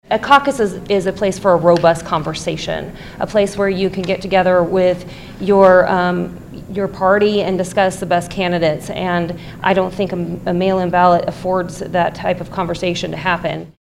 Representative Brooke Boden, a Republican from Indianola, says the bill would maintain the integrity of the Caucus concept.